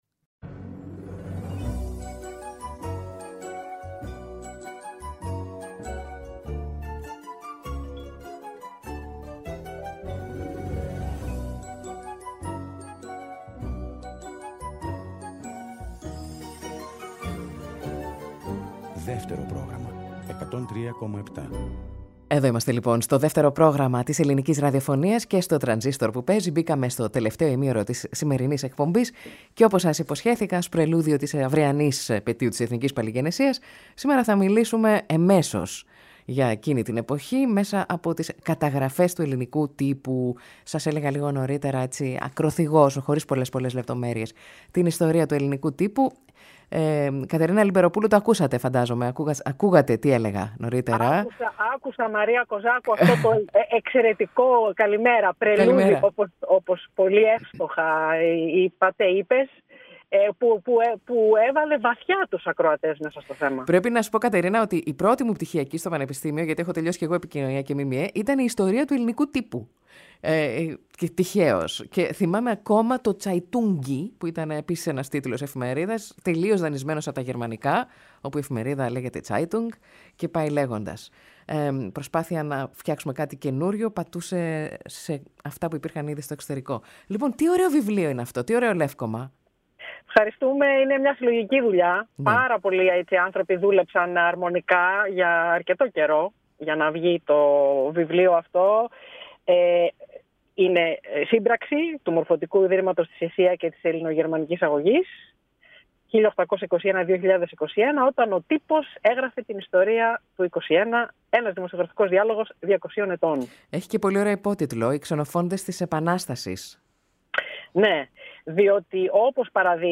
ΔΕΥΤΕΡΟ ΠΡΟΓΡΑΜΜΑ Transistor Συνεντεύξεις